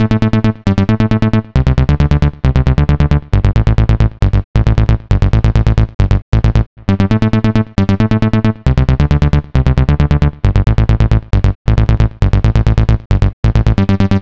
Session 04 - NRG Bass 01.wav